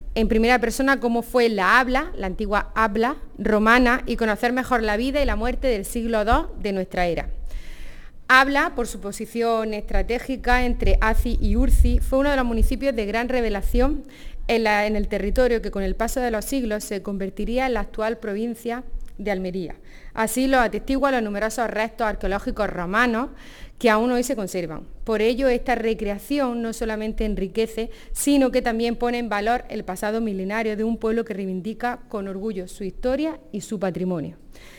02-09_abla__diputada.mp3